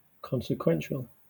Ääntäminen
Southern England
RP : IPA : /ˌkɒnsɪˈkwɛnʃəl/